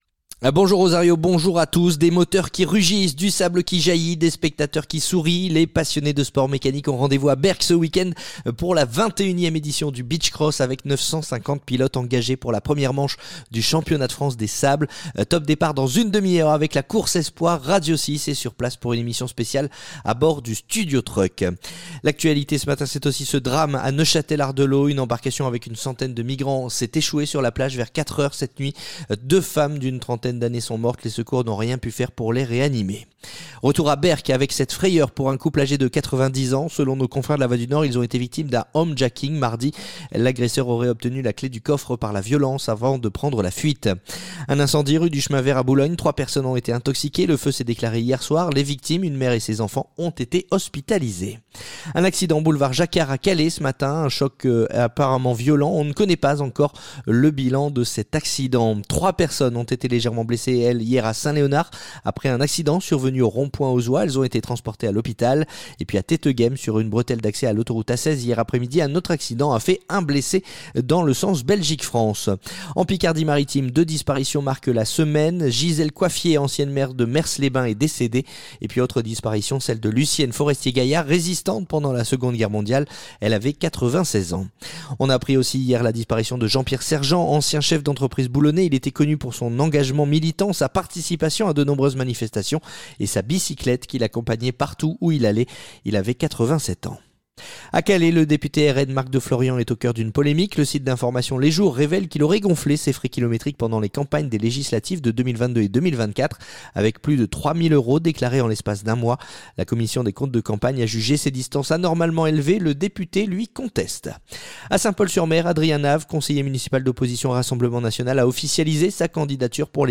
Le journal du samedi 27 septembre